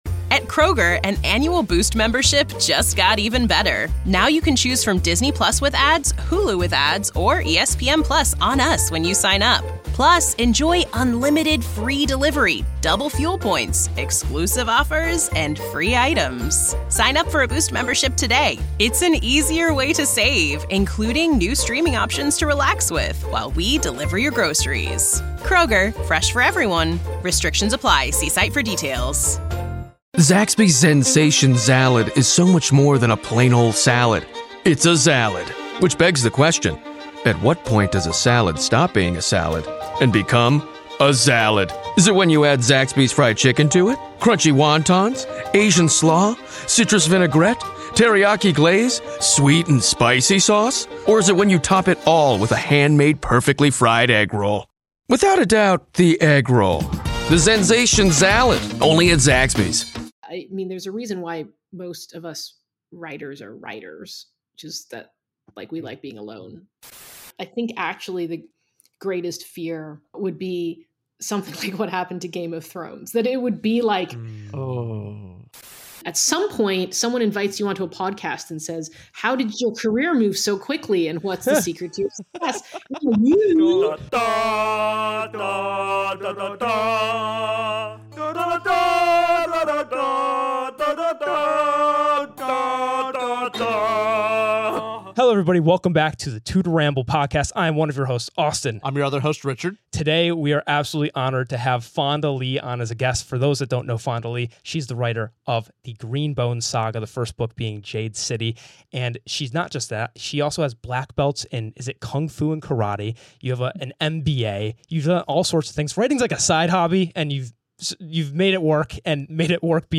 2 guys talking about books (mostly fantasy & sci-fi).